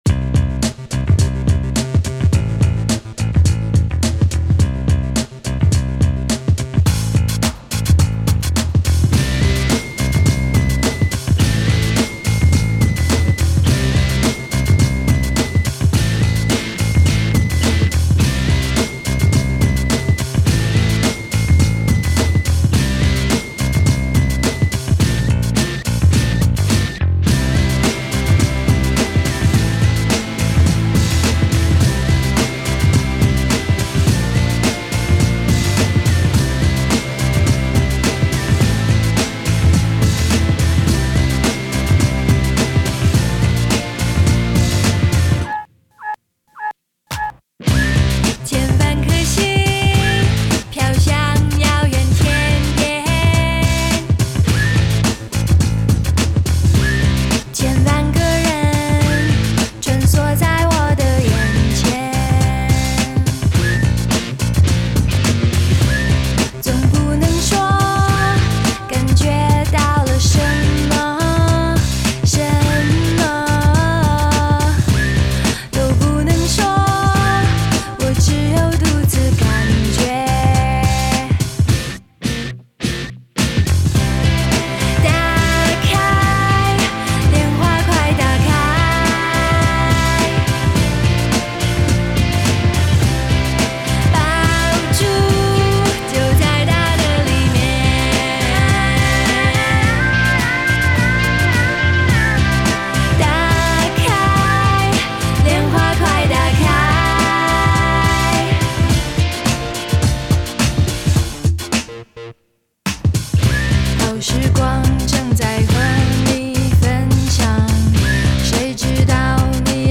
【类别】 内地流行